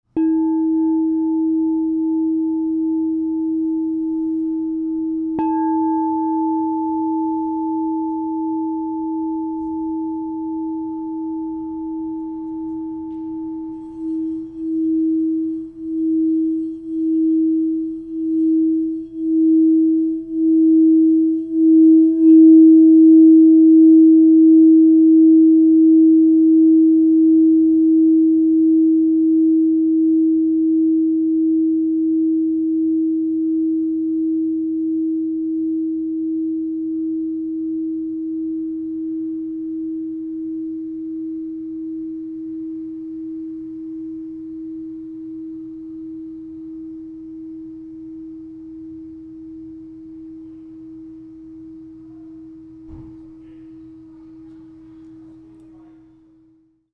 Celestite, Platinum, Palladium (inside) 7″ E -40 Crystal Tones singing bowl
Its compact size ensures portability while delivering profound and resonant tones, making it suitable for both personal and professional practices.
Experience this 7″ Crystal Tones® Celestite alchemy singing bowl made with Platinum, Palladium (inside) in the key of E -40.
432Hz (-)